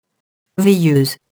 veilleuse [vejøz]